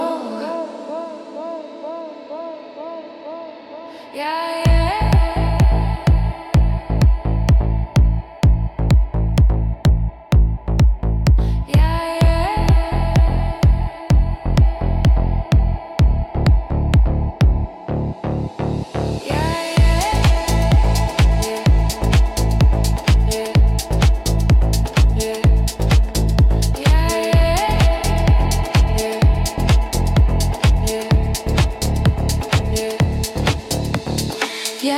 Лёгкий акцент и колоритные инструменты
Afro-Beat African
2025-10-05 Жанр: Украинские Длительность